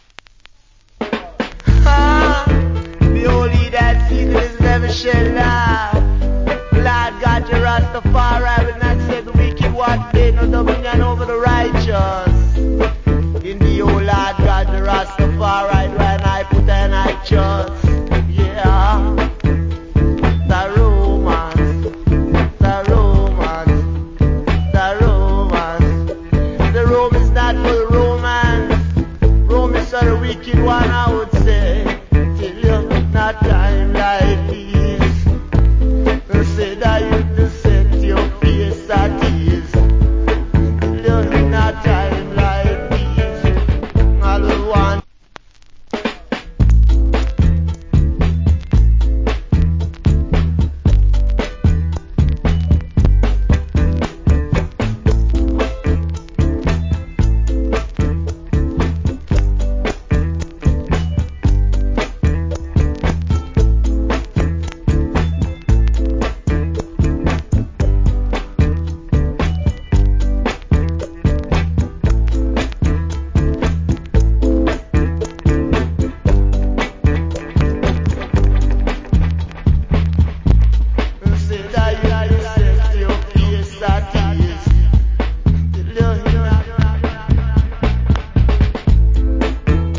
Nice DJ.